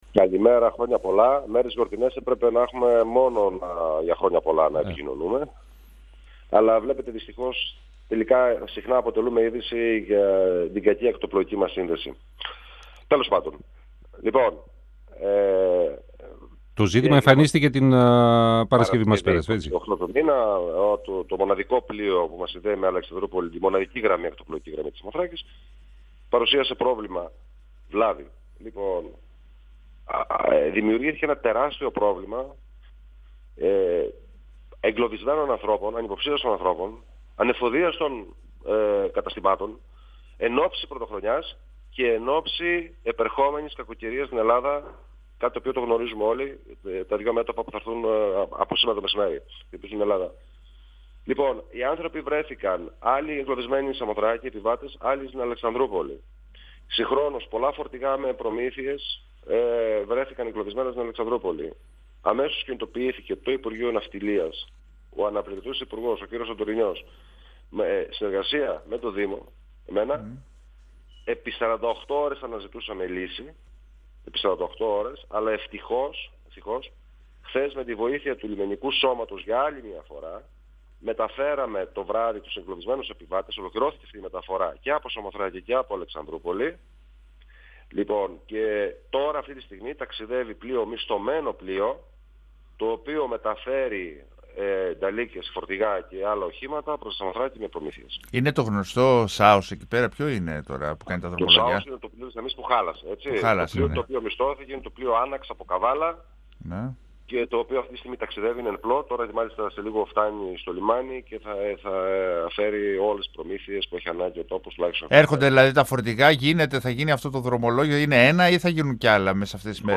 O Θανάσης Βίτσας, στον 102FM του Ρ.Σ.Μ. της ΕΡΤ3
Για ακόμη μία φορά η Σαμοθράκη αποκόπηκε από την ηπειρωτική χώρα. Το «ΣΑΟΣ» το μοναδικό πλοίο που εξυπηρετεί τη γραμμή από και προς την Αλεξανδρούπολη, διέκοψε τα δρομολόγια την προηγούμενη Παρασκευή λόγω βλάβης με αποτέλεσμα κάτοικοι και επισκέπτες να εγκλωβιστούν, ενώ καταστήματα δεν μπόρεσαν να εφοδιαστούν ενόψει της Πρωτοχρονιάς και της επερχόμενης κακοκαιρίας, όπως ανέφερε ο δήμαρχος Σαμοθράκης Θανάσης Βίτσας μιλώντας στον 102FM του Ραδιοφωνικού Σταθμού Μακεδονίας της ΕΡΤ3.